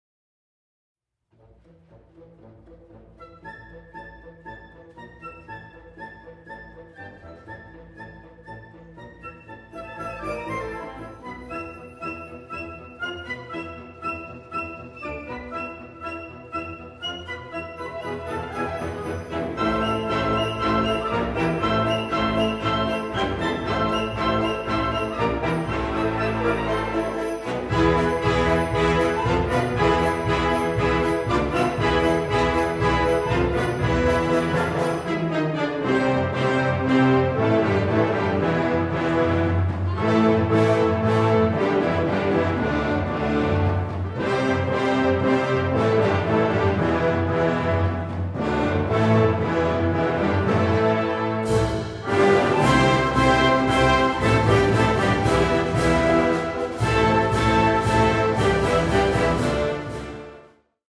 Key : A Minor (original key)
If your band has many players, some players should sing.